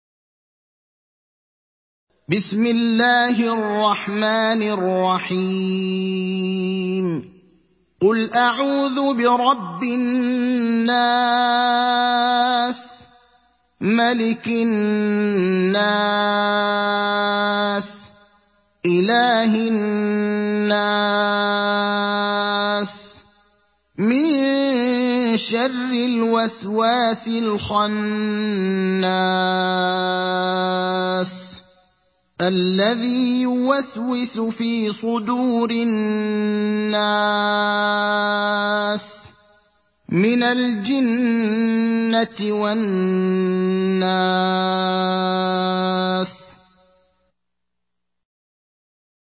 تحميل سورة الناس mp3 بصوت إبراهيم الأخضر برواية حفص عن عاصم, تحميل استماع القرآن الكريم على الجوال mp3 كاملا بروابط مباشرة وسريعة